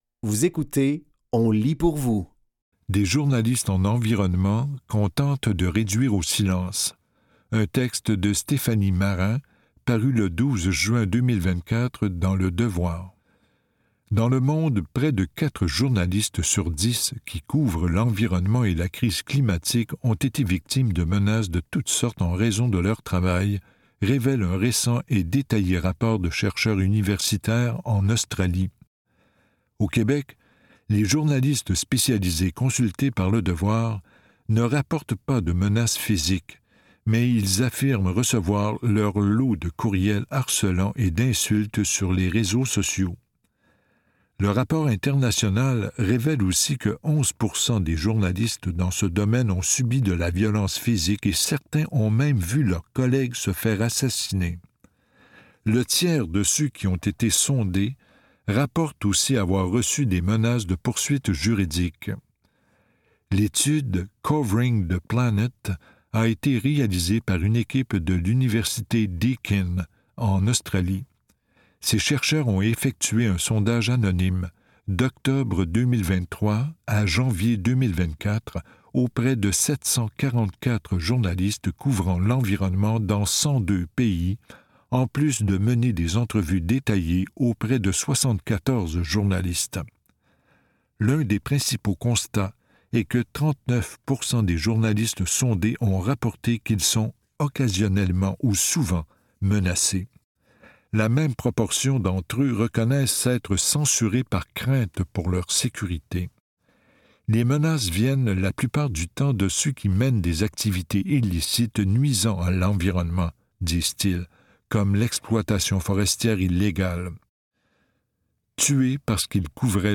Dans cet épisode de On lit pour vous, nous vous offrons une sélection de textes tirés des médias suivants : Le Devoir, La Presse et Québec Science.